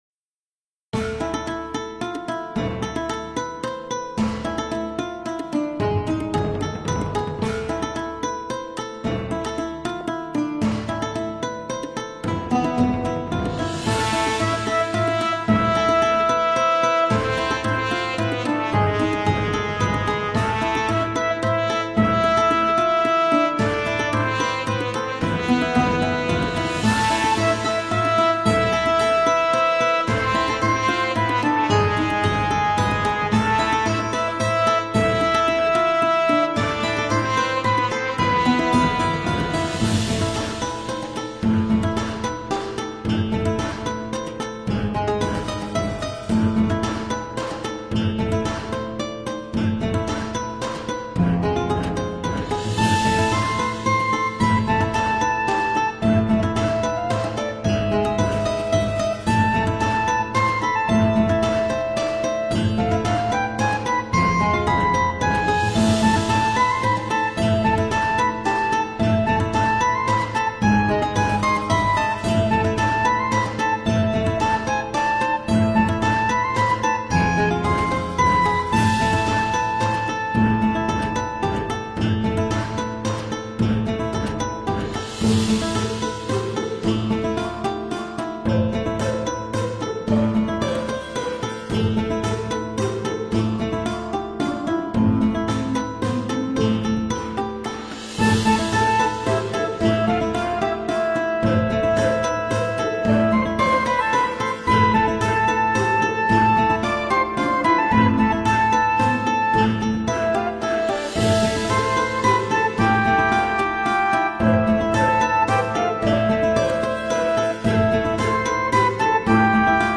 LBhxPGmgWqt_Medieval-music-8.mp3